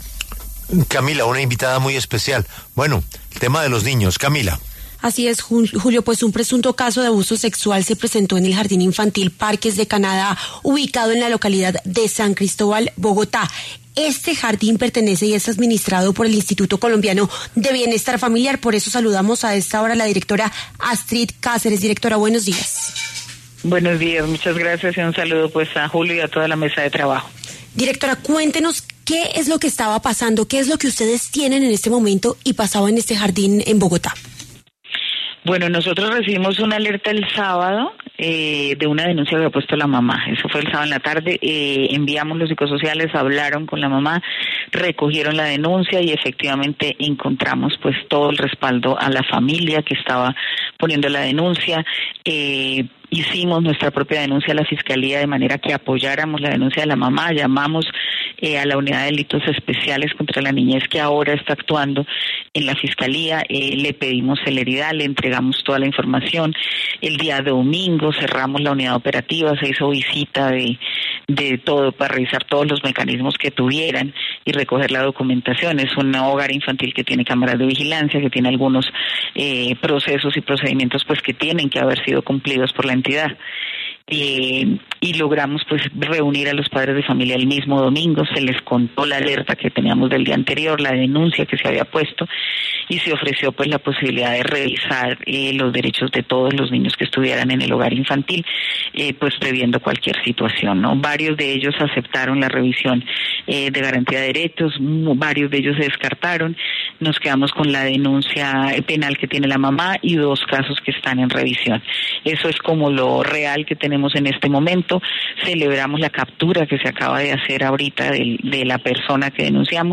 Astrid Cáceres, directora del ICBF, confirmó en entrevista con La W que el instituto recibió la alerta el pasado sábado.